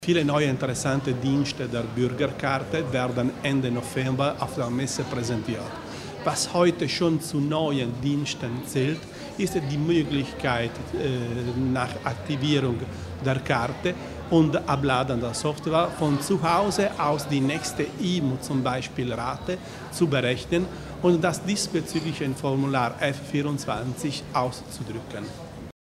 Landesrat Bizzo zur Entwicklung der Bürgerkarte